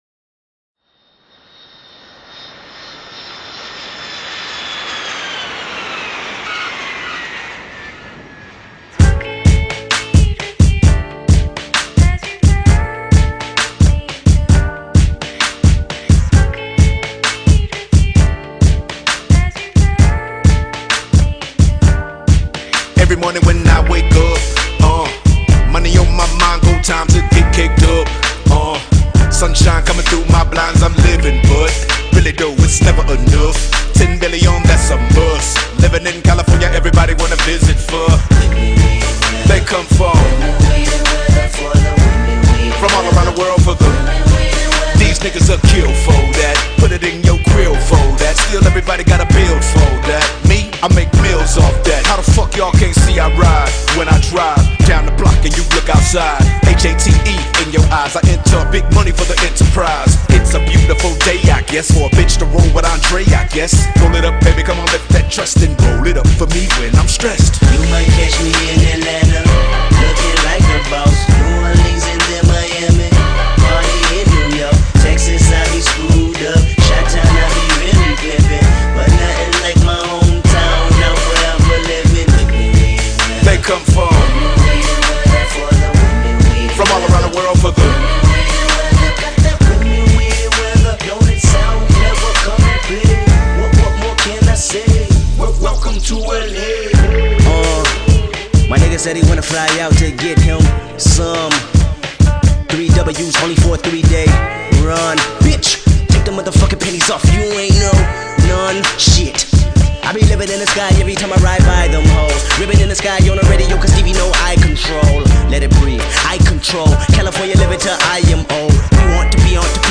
laid back single